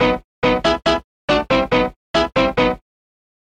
嘻哈霹雳的钢琴刺奏，140 BPM
描述：西班牙风格的Breakbeat Hip Hop Piano Stabs（我认为）...也许？